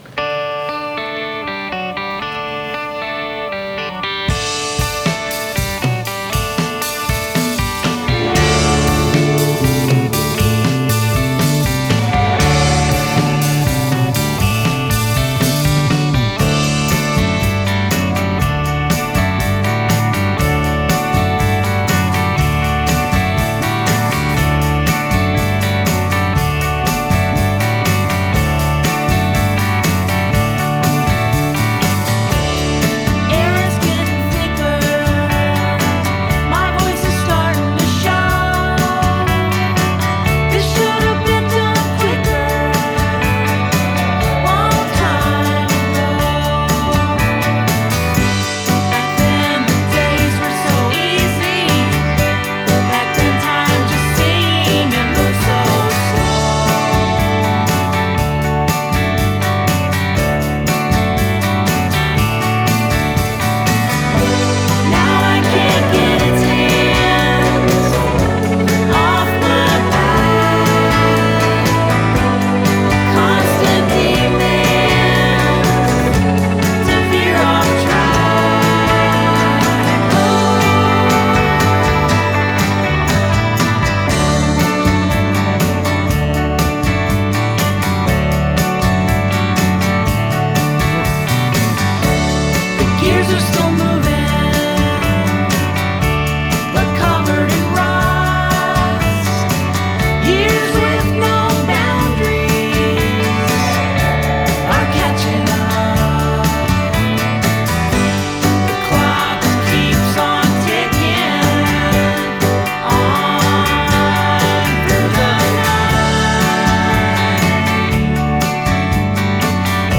broadened the 1960s sound
Bryds-like jangle